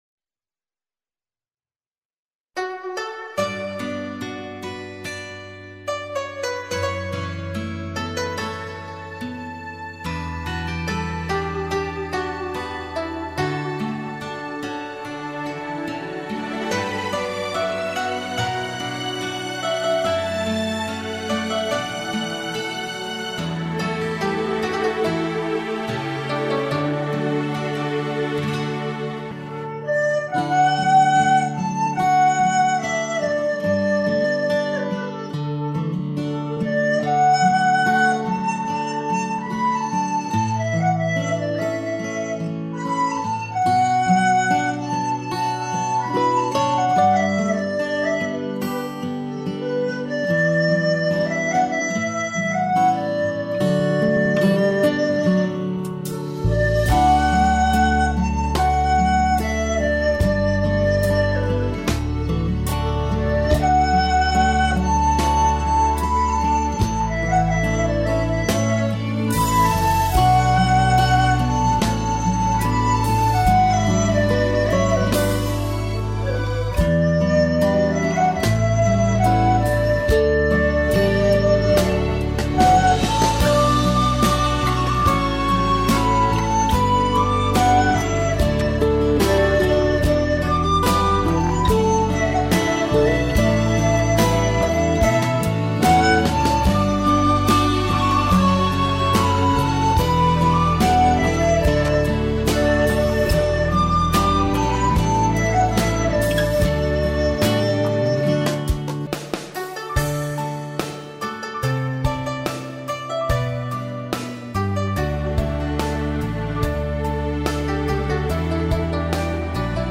曲调悠美笛声悠扬真情悠远!
悠扬清脆动听的笛声给人带来美的享受！
笛仙这曲子吹的，很有自由飞翔的感觉，轻松自在，好听！